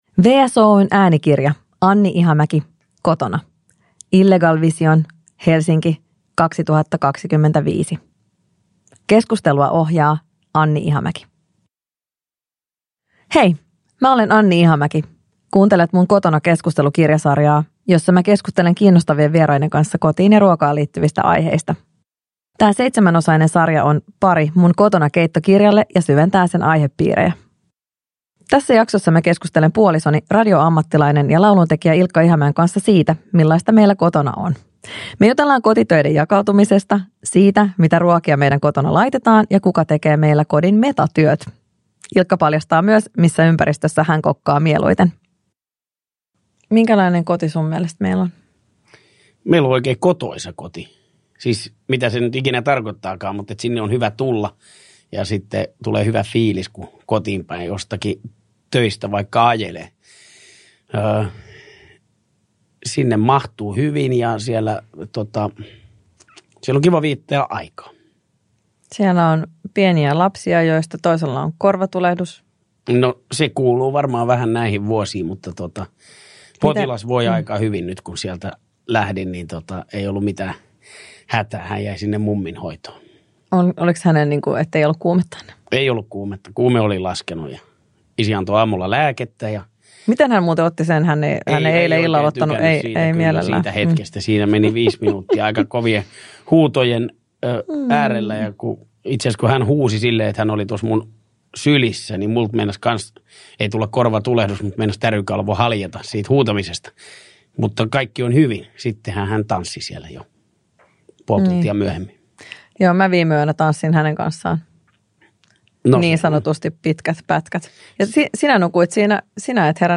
Kotona – Keskusteluja. Kotona ja treffeillä – Ljudbok